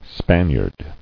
[Span·iard]